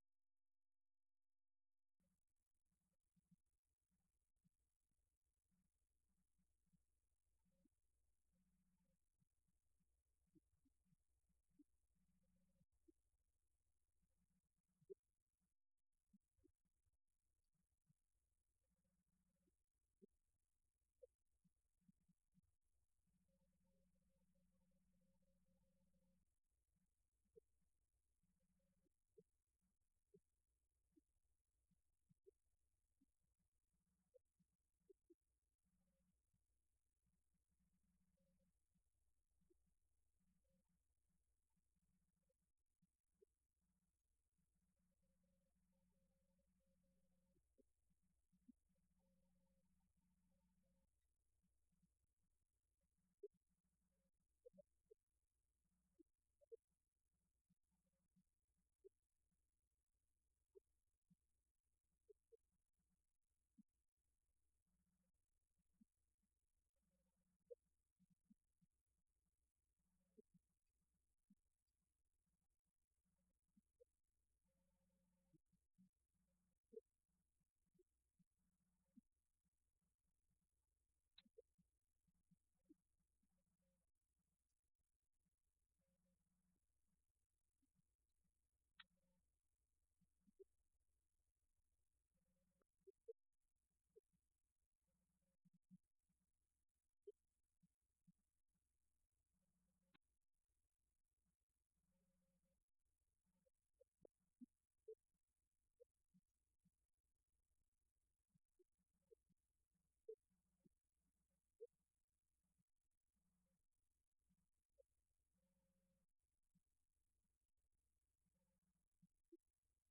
Event: 6th Annual Southwest Spiritual Growth Workshop
Filed Under (Topics): Youth Sessions